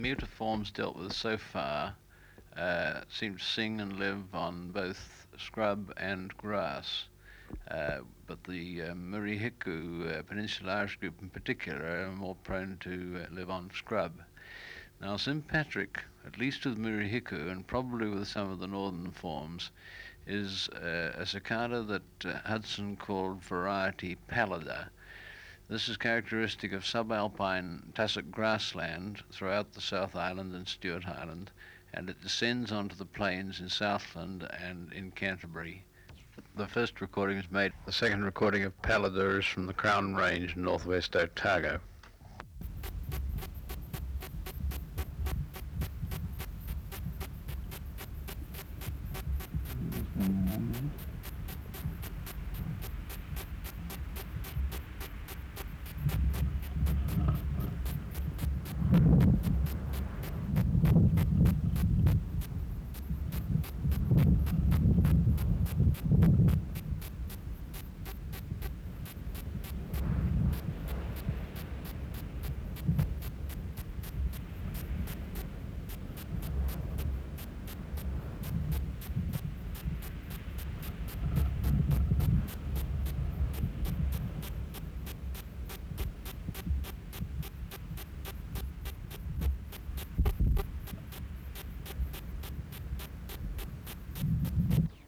Reference Signal: 1 kHz at 12 dB at 1m at intervals
Recorder: Uher portable
14_Kikihia_angusta.wav